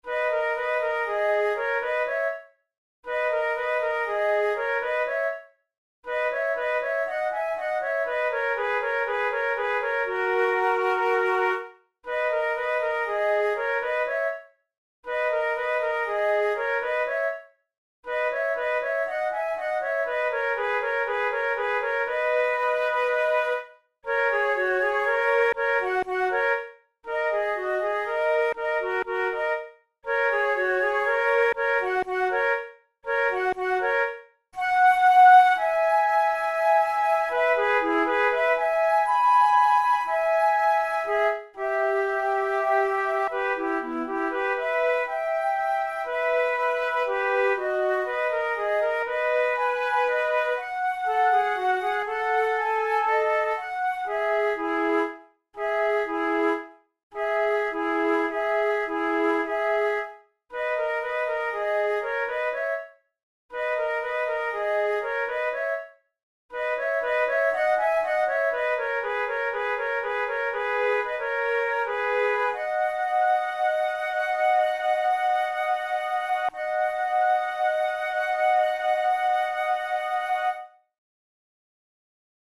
It is taken from a Romantic opera